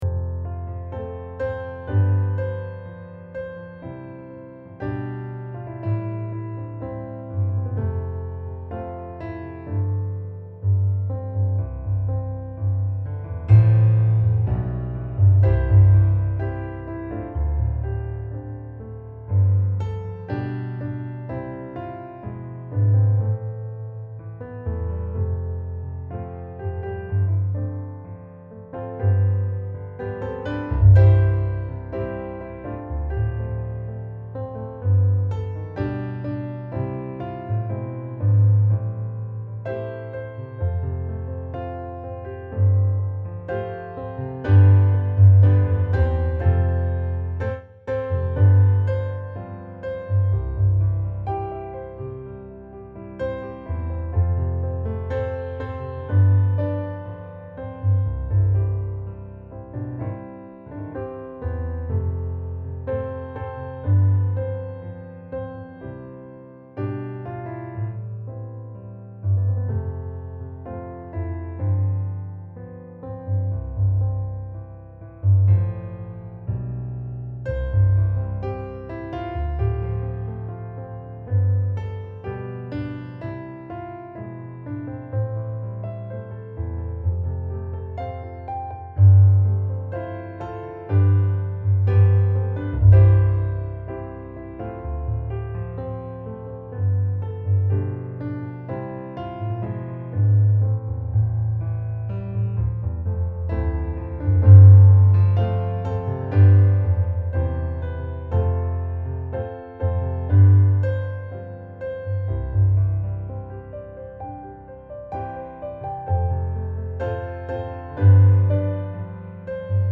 musikbakgrund
Gemensam sång
Musikbakgrund Psalm